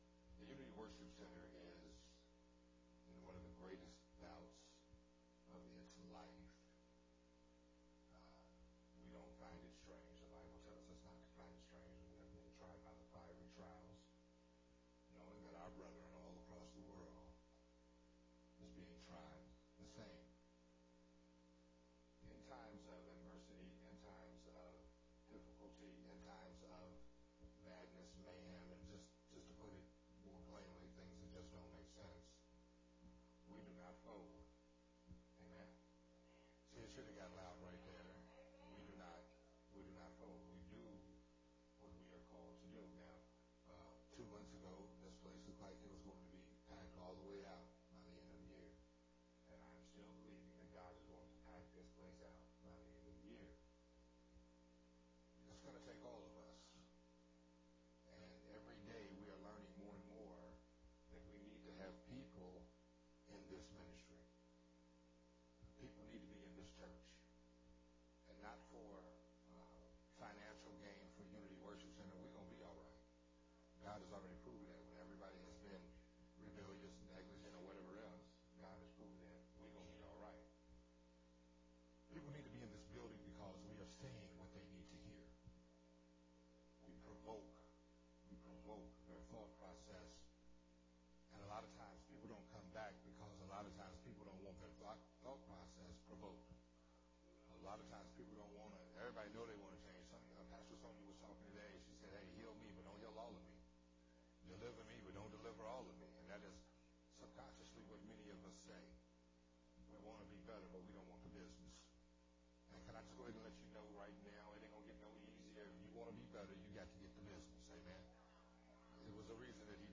sermon
recorded at Unity Worship Center